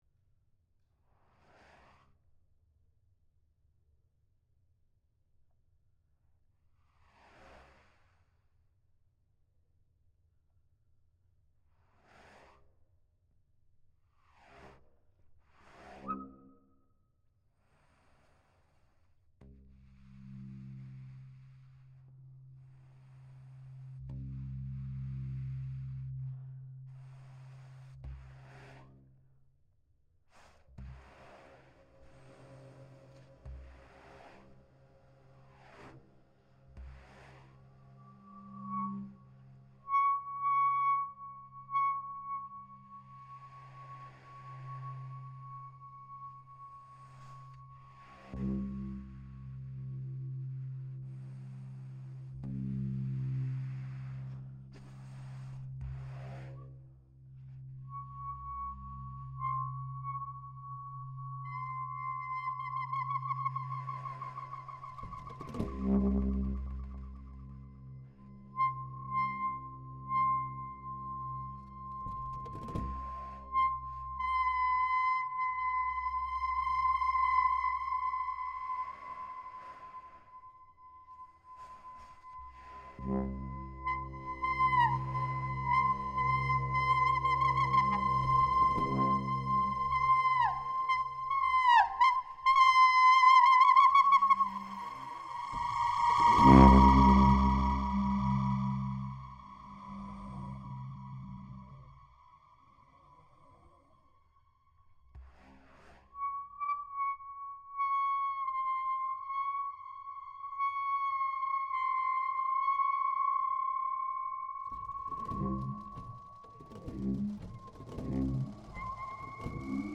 for baritone saxophone & electronics
Baritone Saxophone
Concert IRCAM Cursus 1
April 2, 2015 Centre G. Pompidou, Paris France